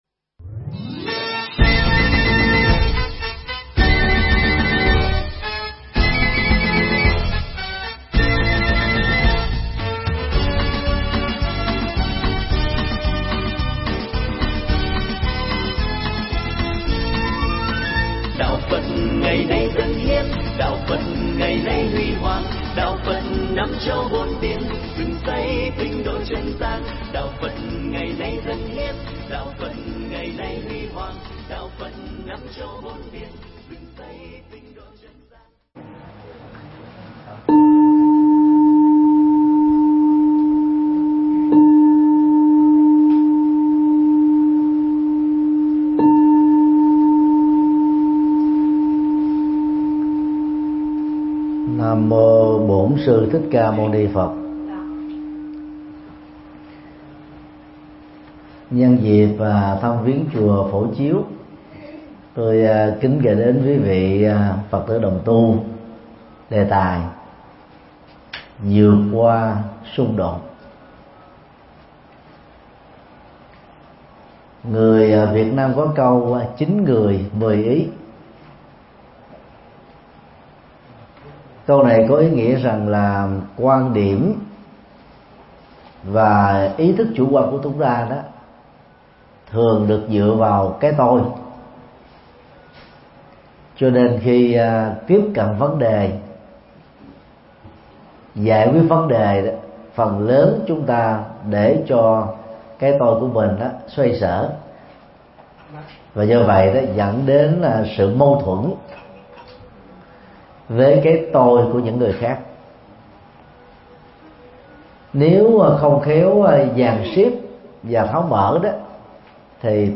Mp3 Pháp Thoại Vượt Qua Xung Đột – Thượng Tọa Thích Nhật Từ giảng tại chùa Phổ Chiếu (Hoa Kỳ), ngày 7 tháng 6 năm 2017